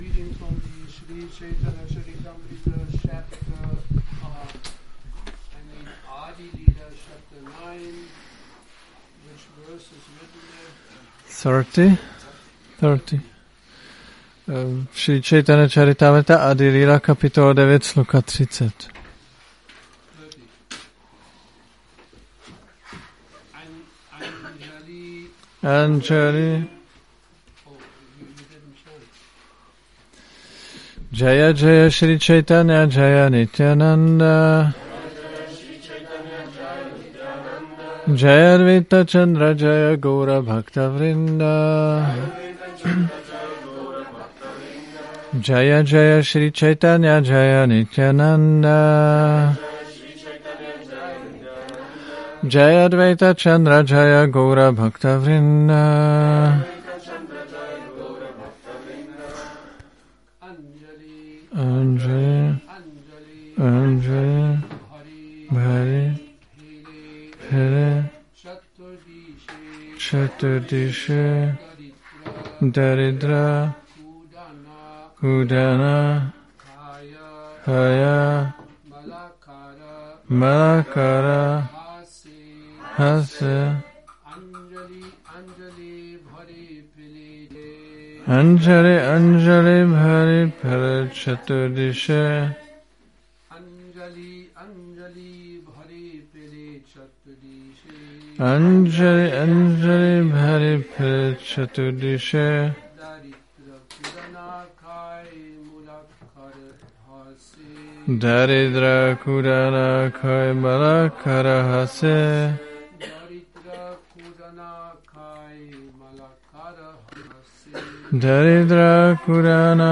Přednáška CC-ADI-9.30